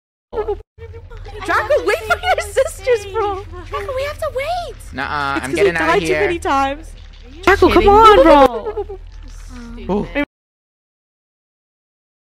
(sorry if it hurt ur ears)